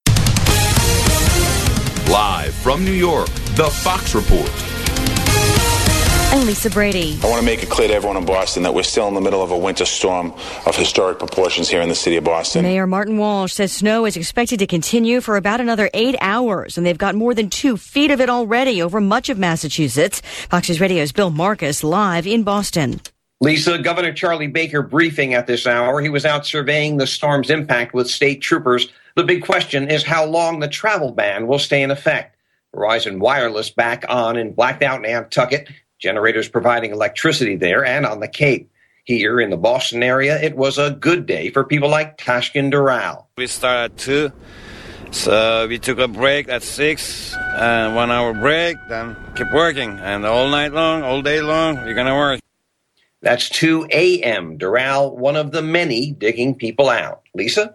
5PM LIVE